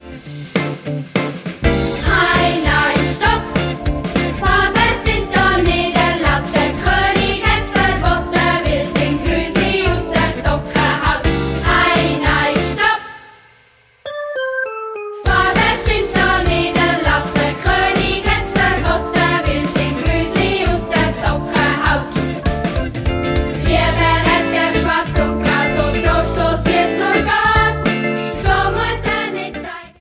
Schulmusical